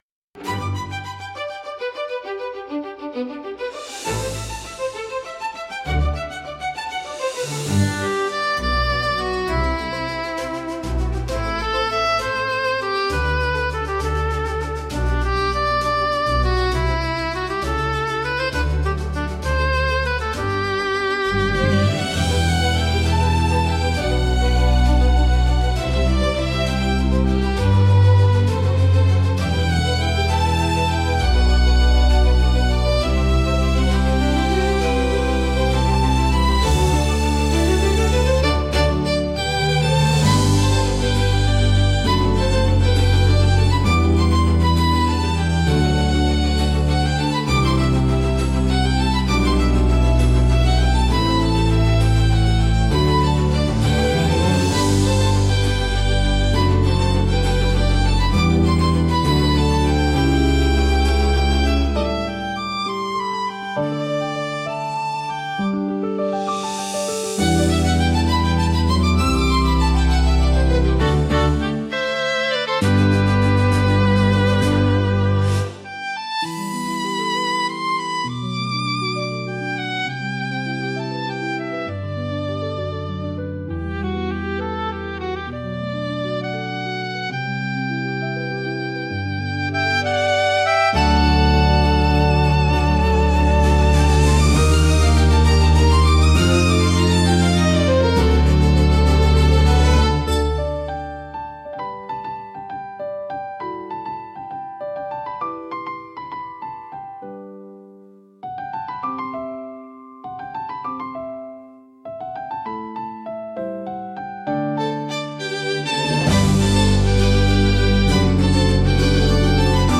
ロマンティックで華やかな場にぴったりのジャンルです。